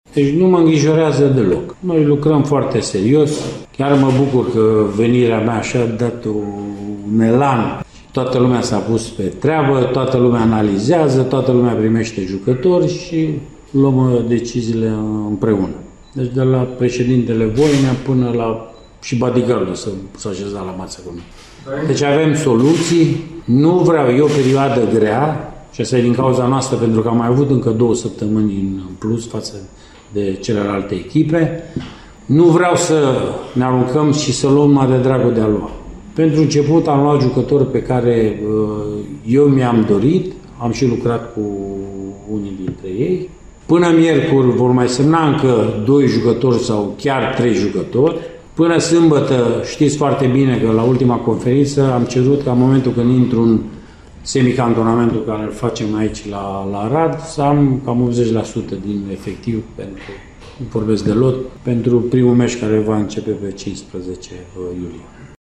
Dincolo de acest lucru, Rednic a vorbit despre „revoluţia” din această vară, nu mai puţin de 15 jucători părăsind prim-divizionara: